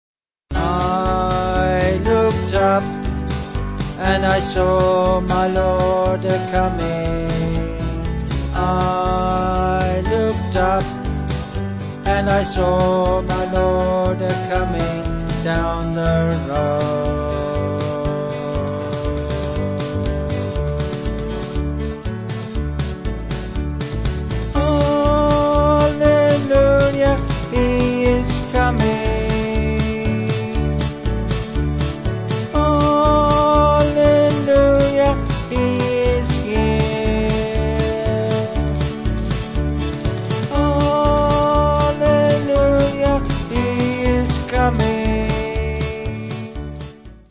Vocals & Band